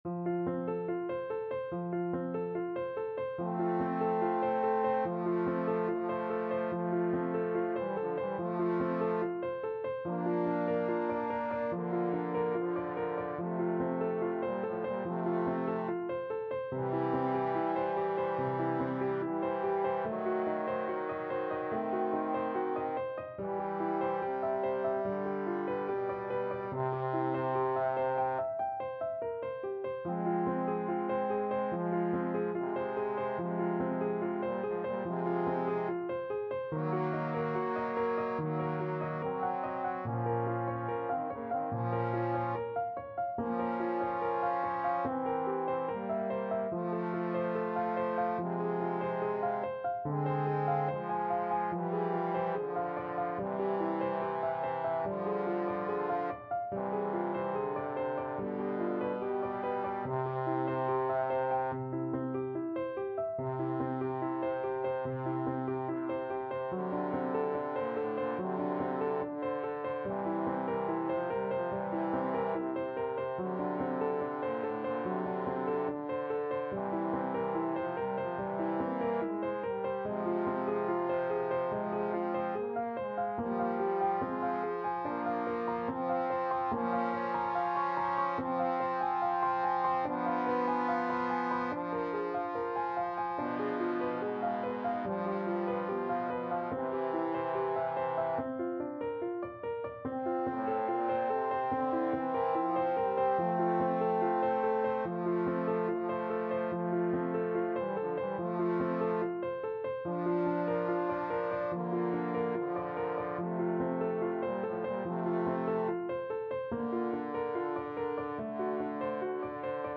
Andante =72
Trombone Duet  (View more Intermediate Trombone Duet Music)
Classical (View more Classical Trombone Duet Music)